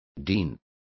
Also find out how decano is pronounced correctly.